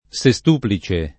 sestuplice [ S e S t 2 pli © e ] agg.